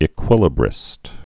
(ĭ-kwĭlə-brĭst)